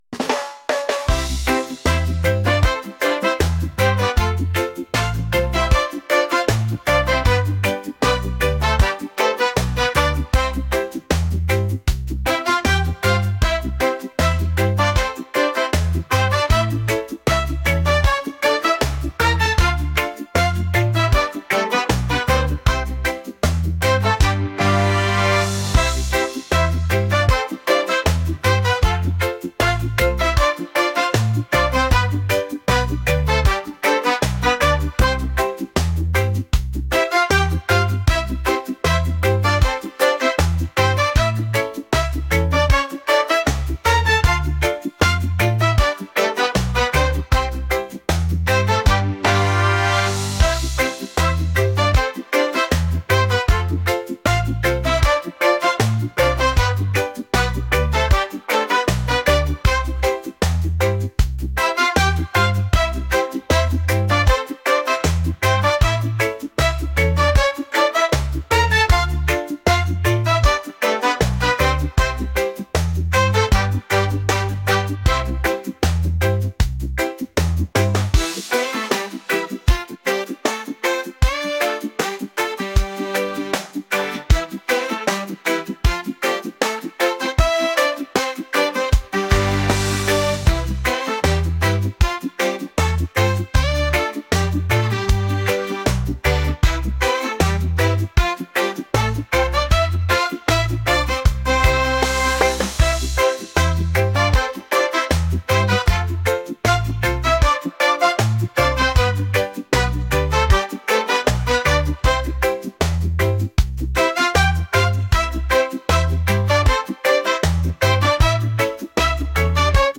reggae | pop